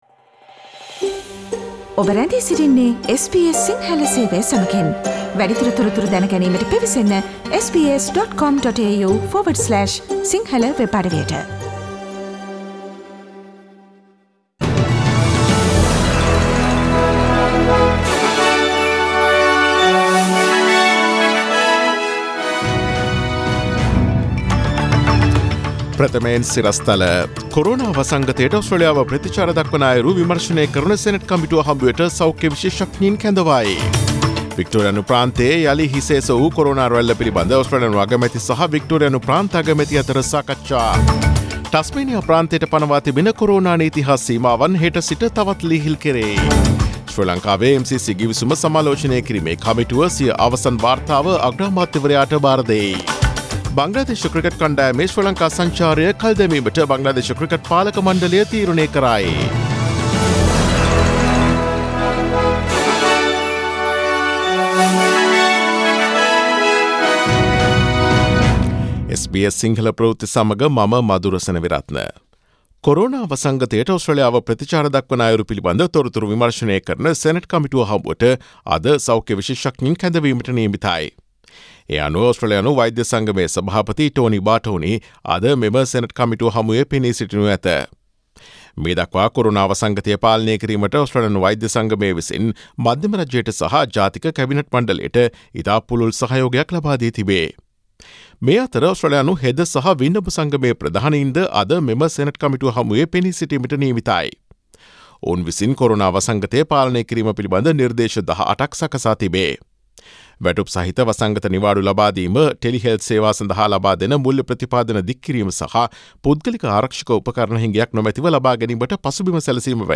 Daily News bulletin of SBS Sinhala Service: Thursday 25 June 2020